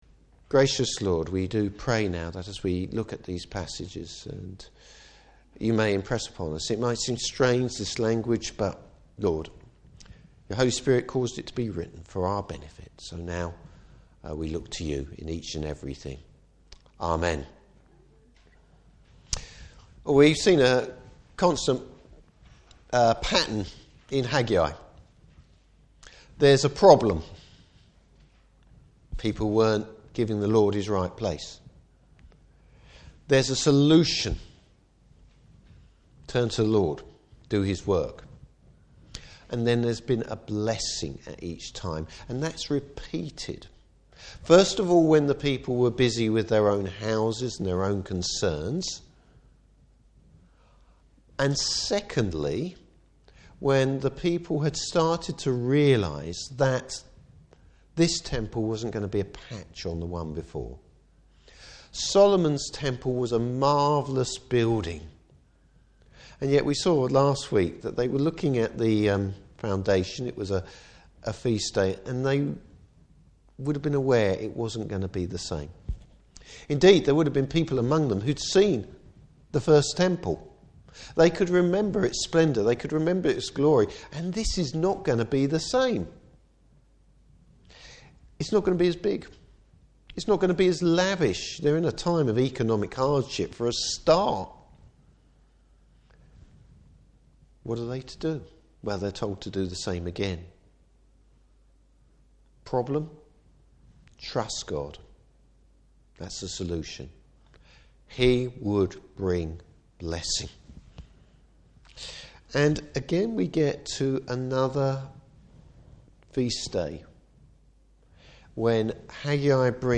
Service Type: Morning Service Encouragement comes from the Lord! Topics: Can there be blessing in difficult times?